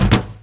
FallImpact_Metal 01.wav